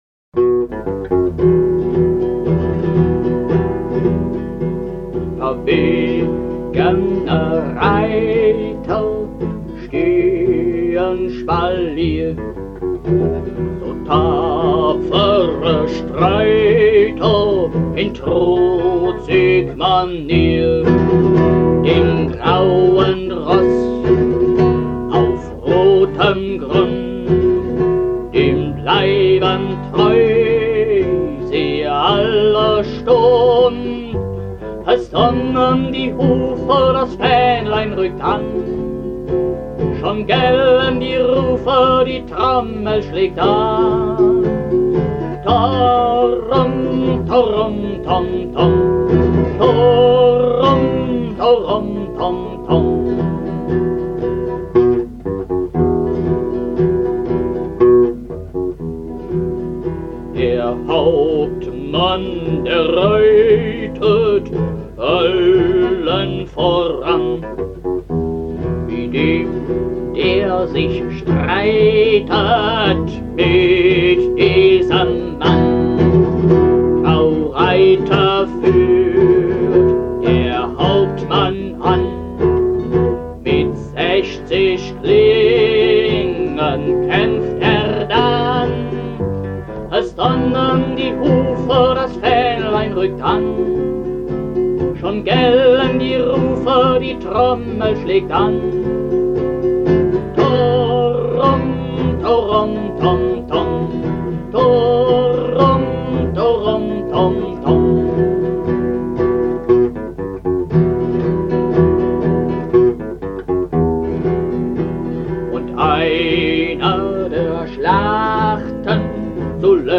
Meine frühen Lieder